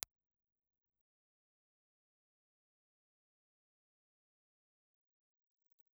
Impulse Response File:
Impulse Response file of the STC 4136 condenser microphone.
The STC 4136 is a small cardioid condenser microphone.